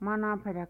pronounced).
Mą̄́ną́pera.mp3